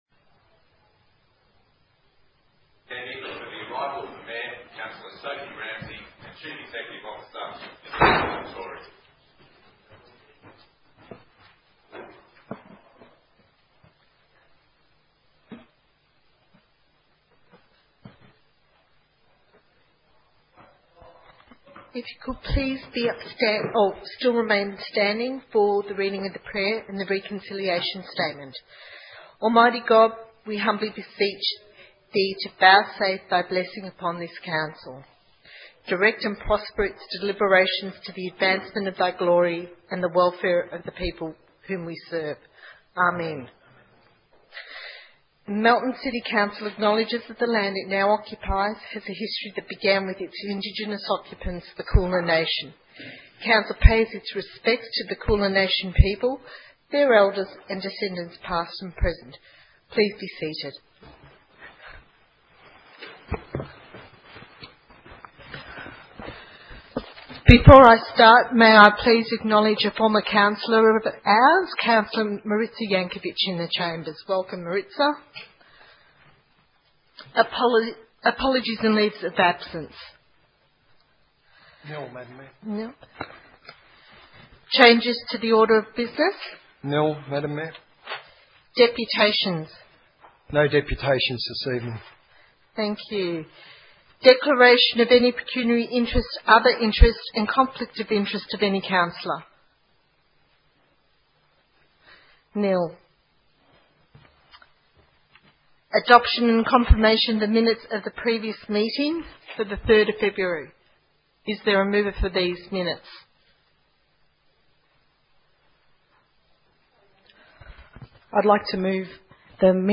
10 March 2015 - Ordinary Council Meeting